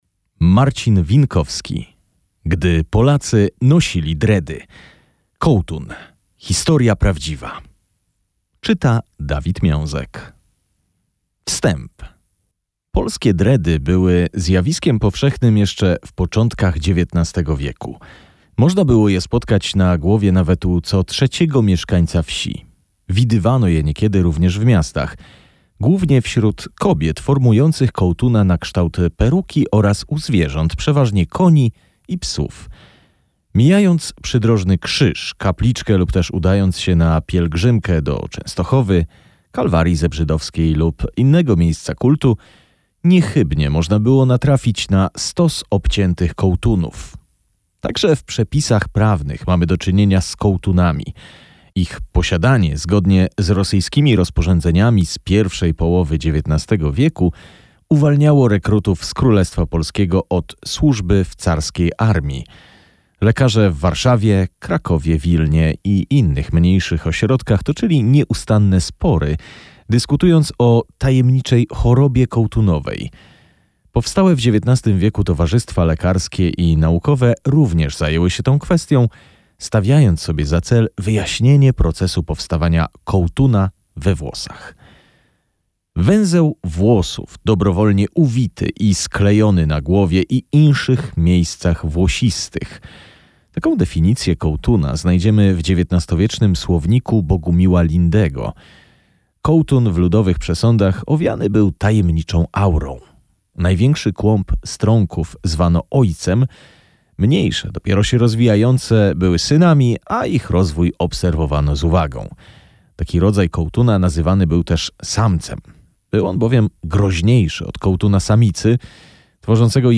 Audiobook dostępny również jako e-book .